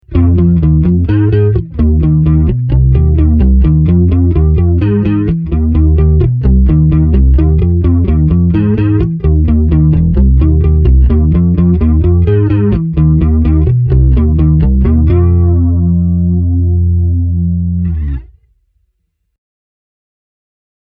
Seuraavat pätkät on äänitetty Squier Jazz -bassolla (vm. 1985) suoraan XLR-lähdön kautta Apple Garagebandiin:
Zoom B3 – flanger